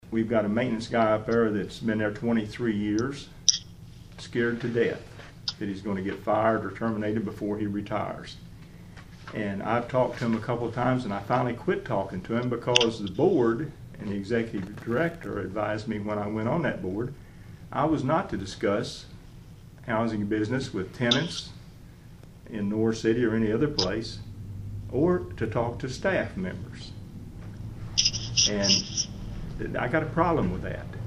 Both men were at the White County Board meeting Tuesday night and for nearly 25 minutes, laid out their concerns with how the agency is being run.